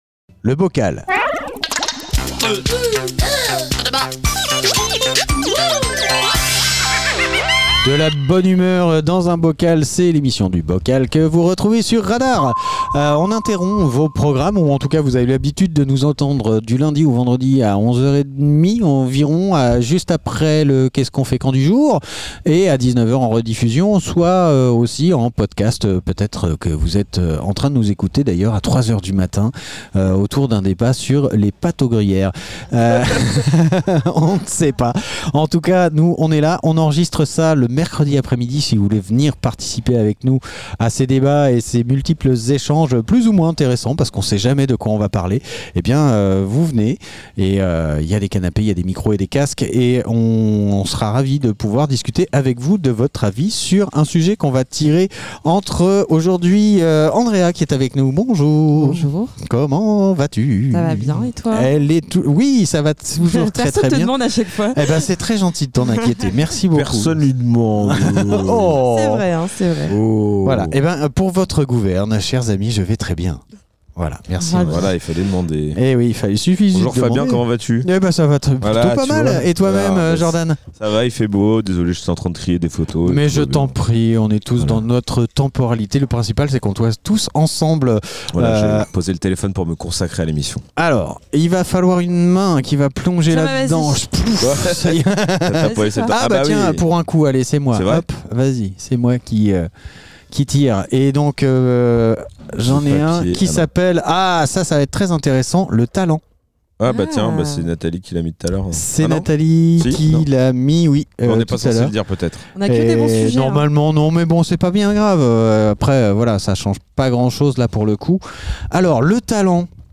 Une poignée d'invités propose des sujets de débats à bulletin secret. Ces petits papiers sont délicatement mélangés pour n'en tirer qu'un seul au sort. Le sujet, une fois dévoilé, donne lieu à des conversations parfois profondes, parfois légères, toujours dans la bonne humeur !